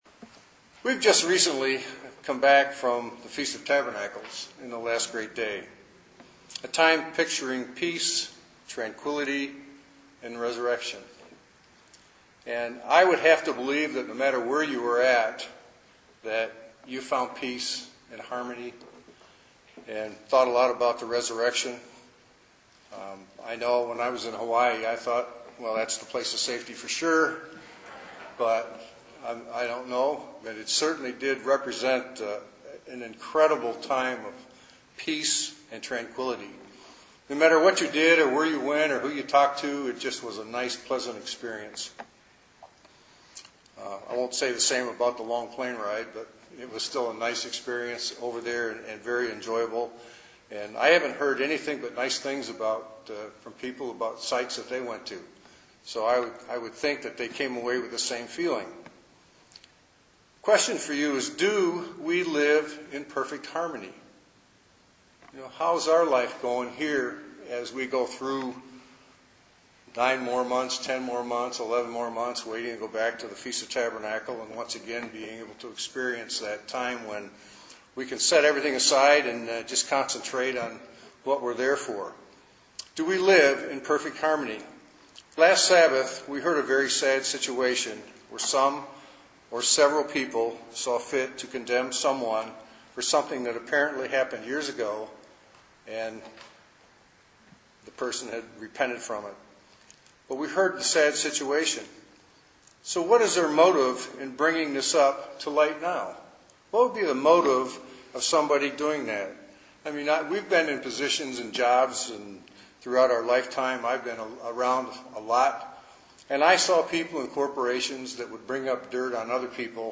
Given in Ann Arbor, MI